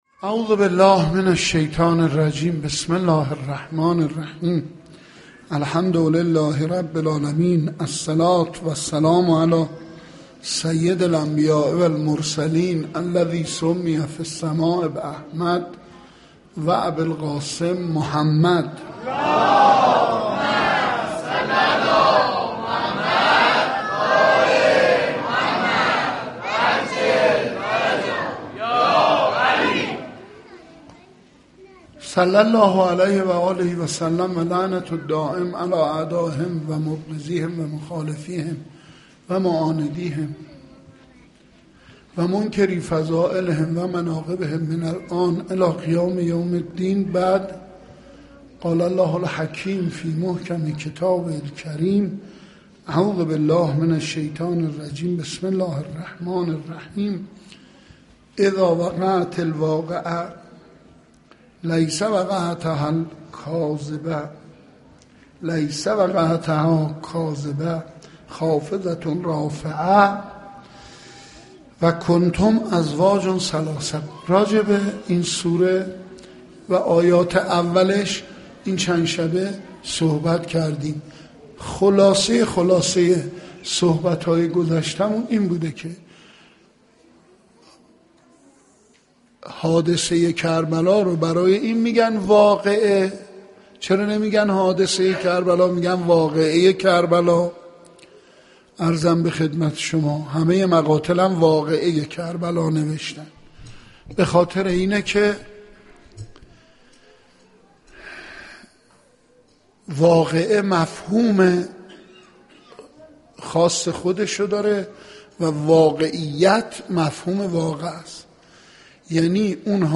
خیمه گاه - حسینیه کربلا - کربلا حادثه یا واقعه (جلسه سوم) - شب سوم محرم 1389
سخنرانی